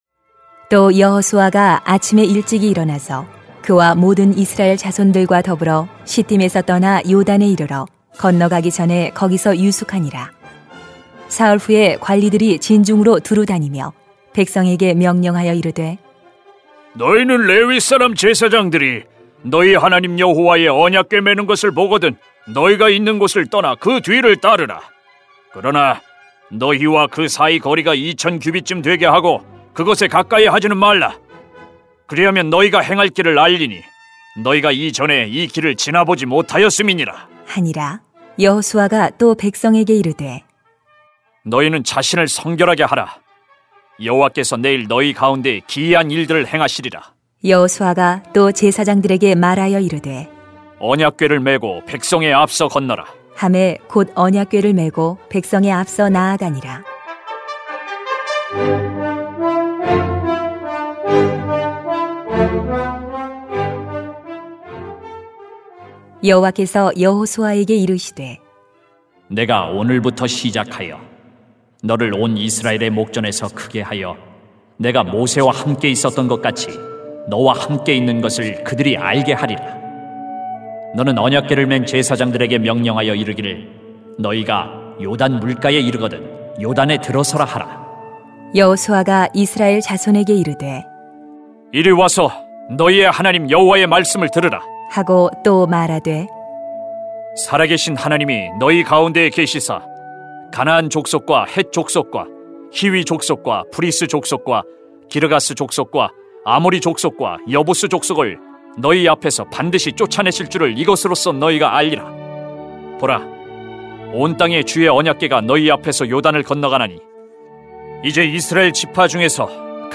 [수 3:1-17] 한계를 넘어서는 영적 리더십 > 새벽기도회 | 전주제자교회